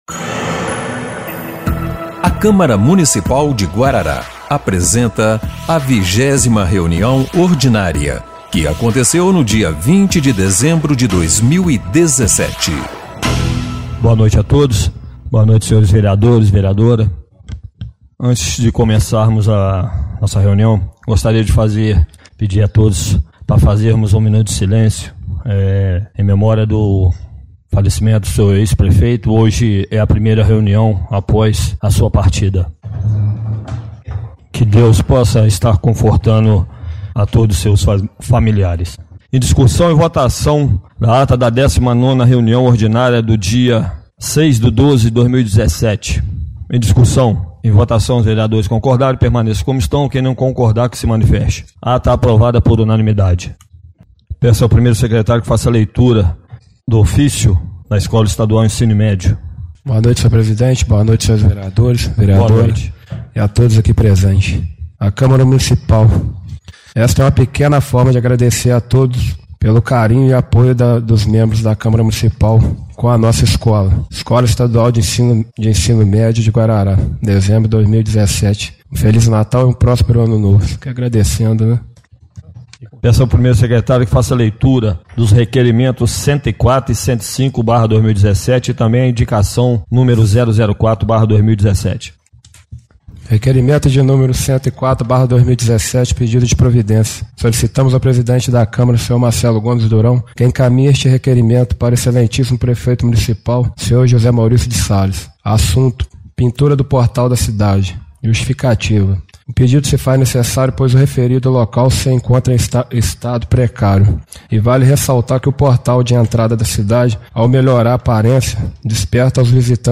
20ª Reunião Ordinária de 20/12/2017